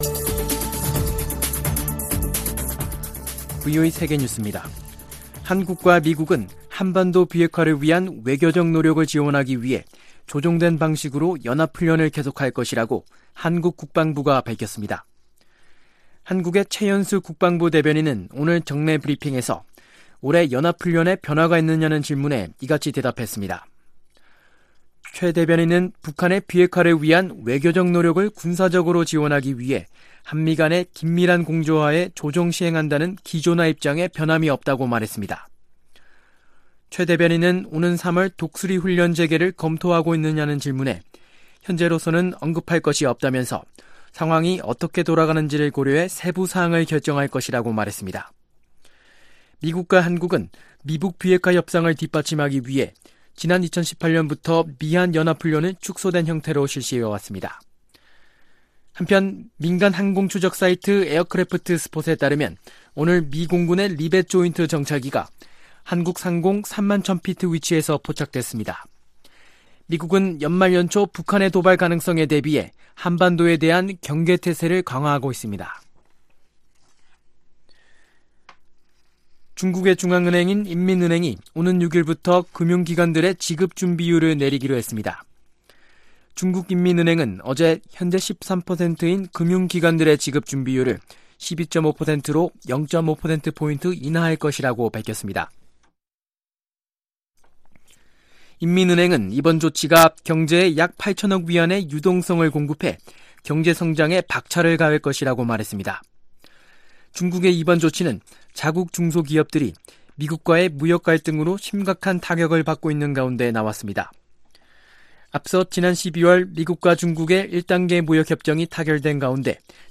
VOA 한국어 간판 뉴스 프로그램 '뉴스 투데이', 2020년 1월 2일 3부 방송입니다. 김정은 북한 국무위원장은 당 전원회의 보고를 통해 미국의 기존 입장에 변화가 없는데 대한 불만을 나타냈다고 미국의 전직 관리들이 분석했습니다. 미국 언론들은 북한의 핵실험과 대륙간 탄도미사일 시험발사 재개 가능성에 주목하면서도 섯불리 중대한 도발을 하기는 어려울 것으로 내다보았습니다.